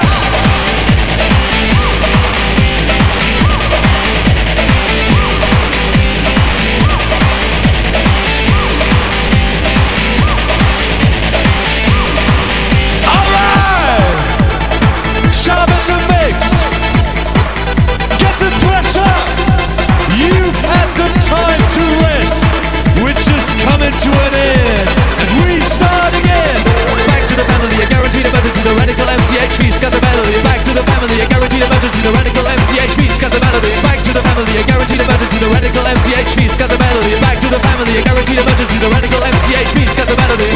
fast-dl / cstrike / sound / ambience / spfire.wav
spfire.wav